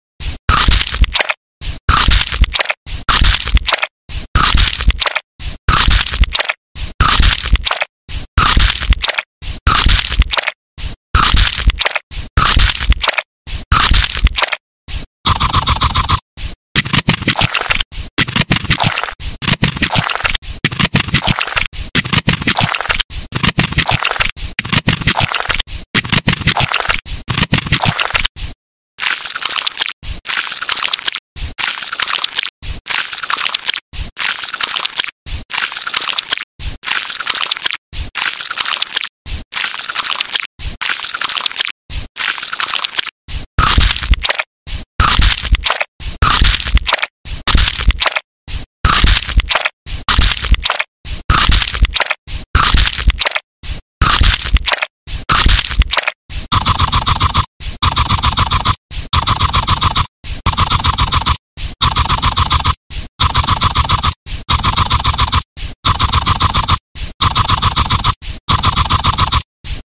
ASMR foot thumb spa massage sound effects free download